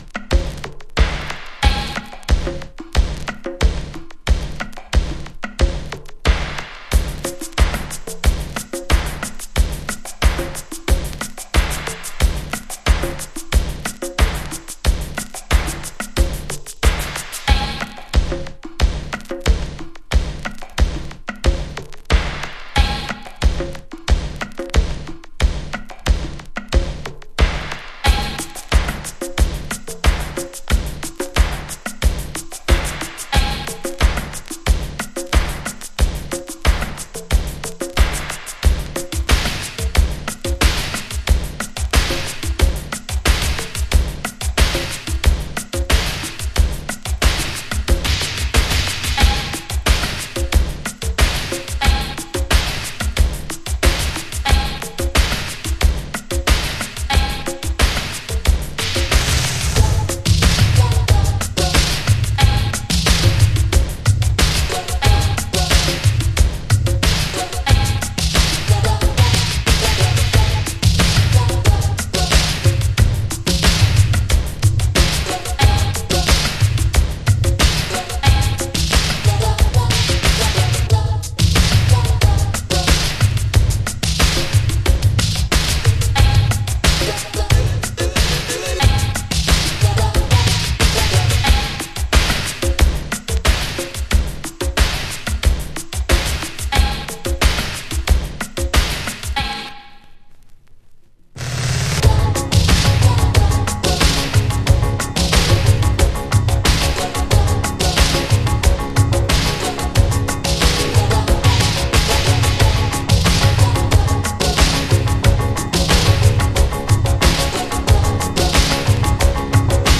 No Vox Mix